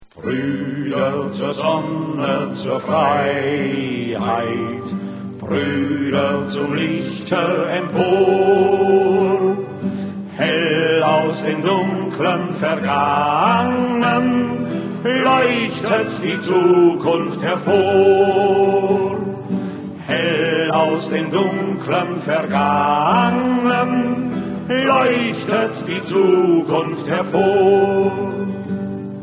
Arbeiterlieder